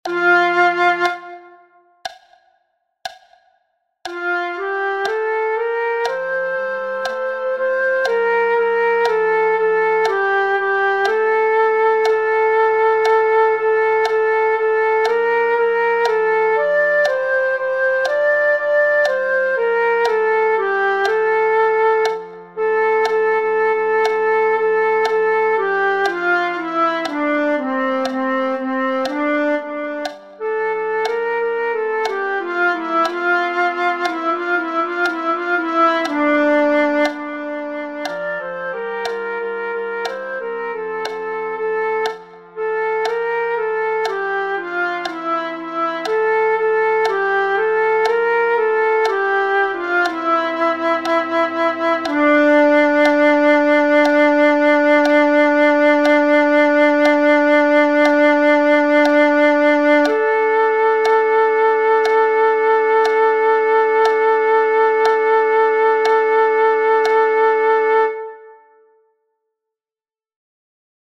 La Rosa Bianca - Soprani